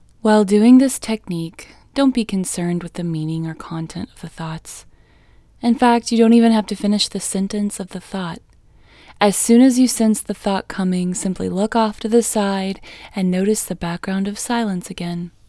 LOCATE IN English Female 19